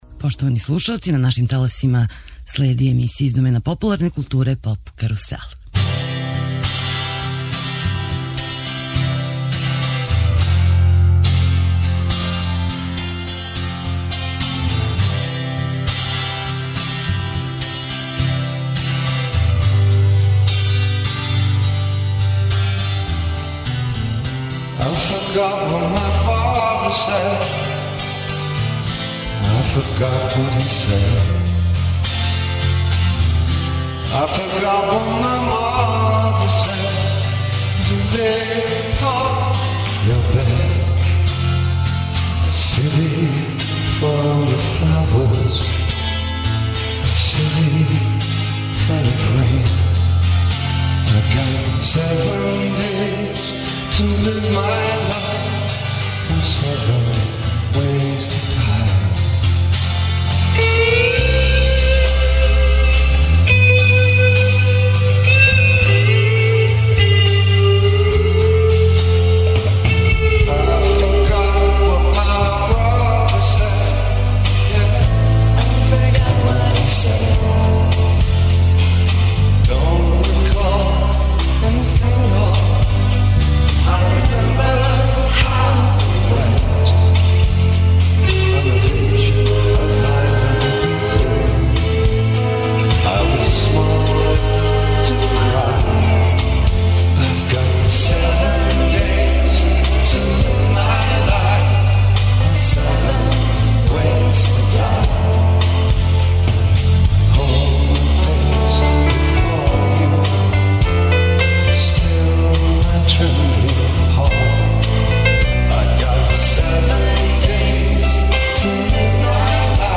гошће у студију